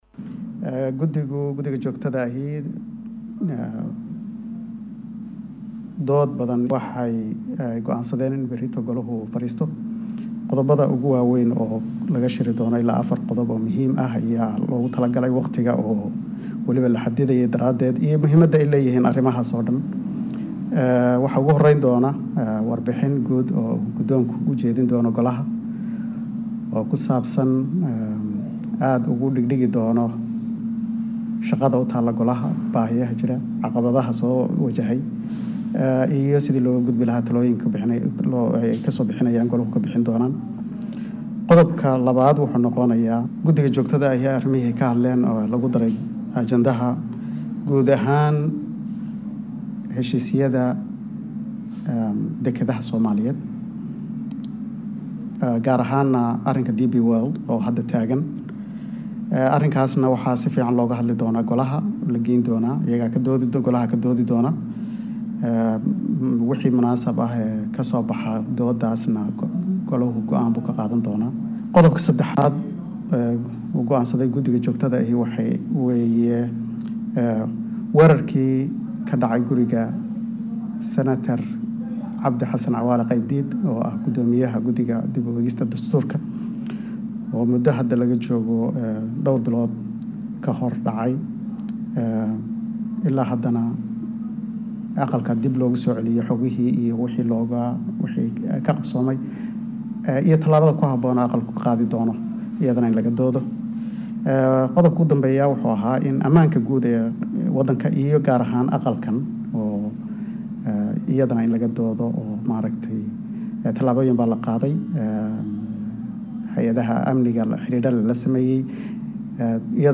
Halkaan ka dhageyso codka xogahayah guud ee aqalka sare.